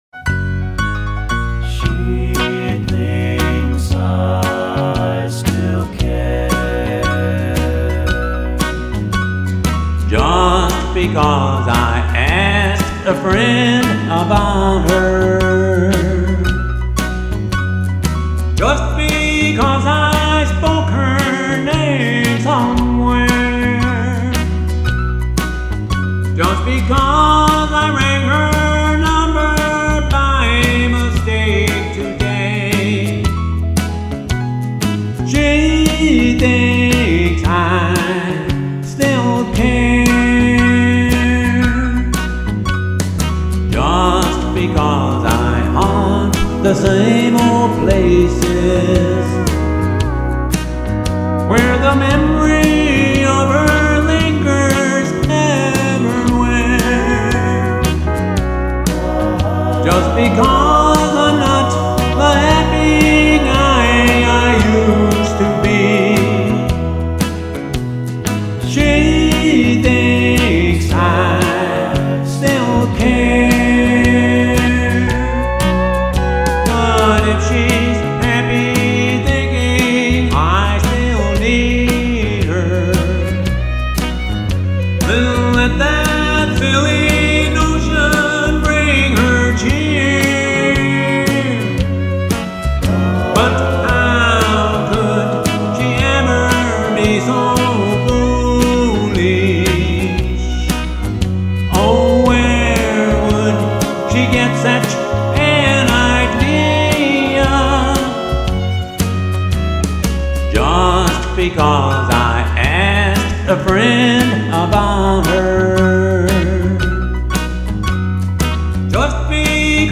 Country Blues & Boogies